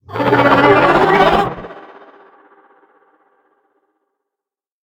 Sfx_creature_pinnacarid_callout_01.ogg